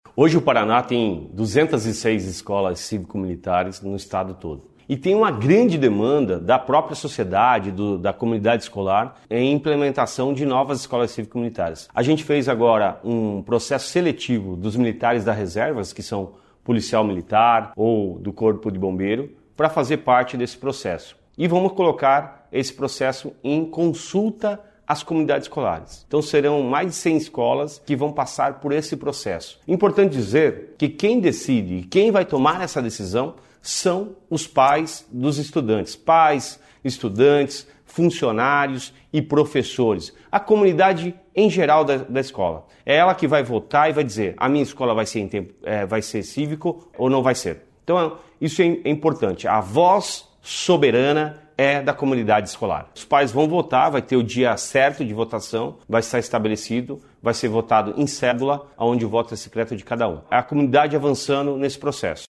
Sonora do secretário da Educação, Roni Miranda, sobre a consulta pública para comunidade escolar decidir sobre modelo cívico-militar